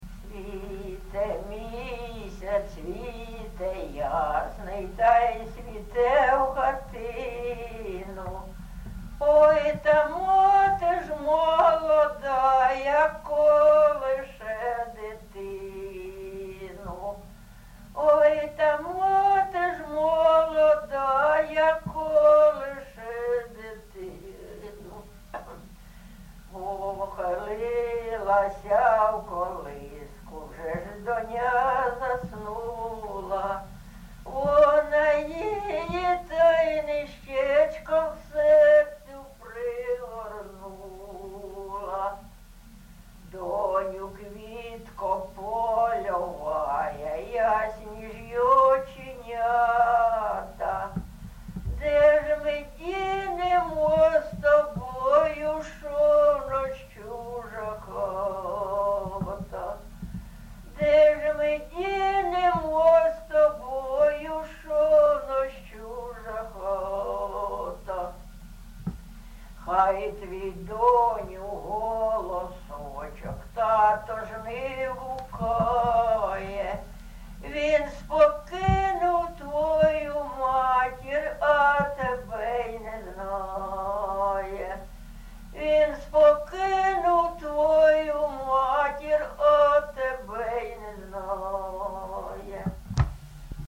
ЖанрПісні з особистого та родинного життя, Пісні літературного походження
МотивНещаслива доля, Журба, туга
Місце записус. Софіївка, Краматорський район, Донецька обл., Україна, Слобожанщина